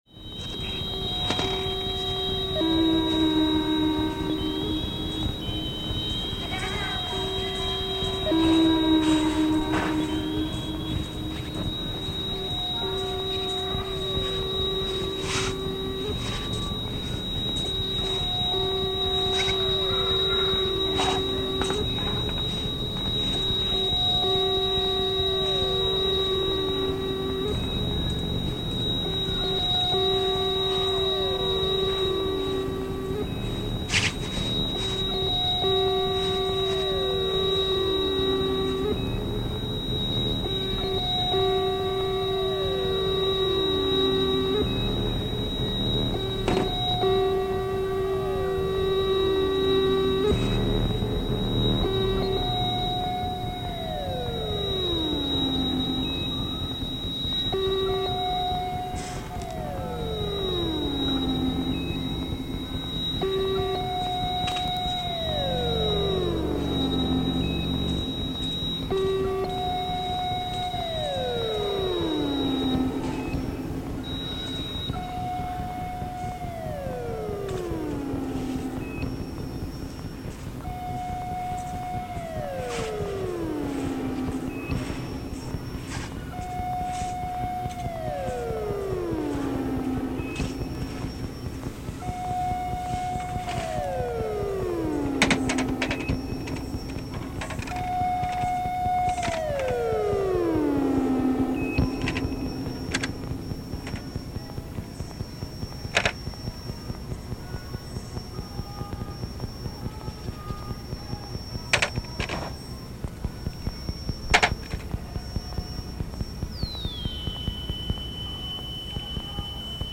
60分カセットテープ＋ハンドメイド6ページ・コラージュ・ブック